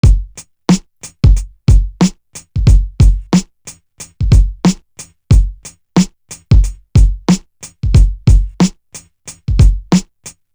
Ninety One Drum.wav